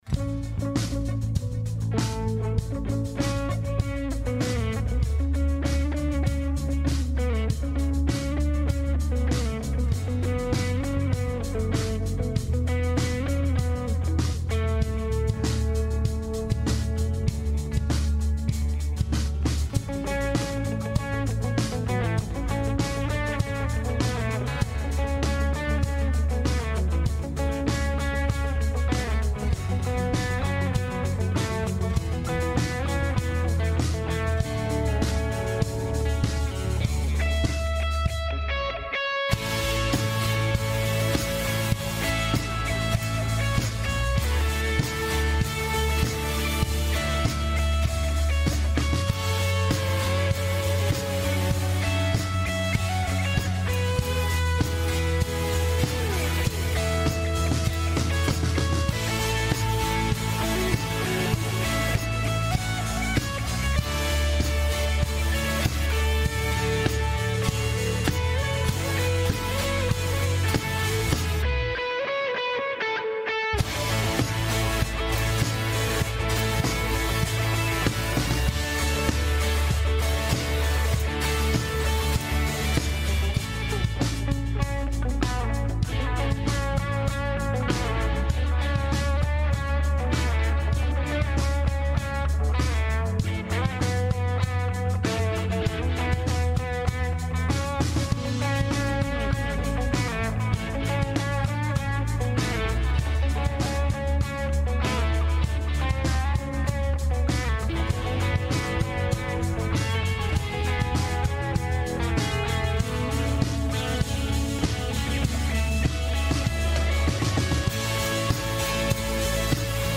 Westgate Chapel Sermons Asking For A Friend - Can I Trust That The Bible Is Authentic?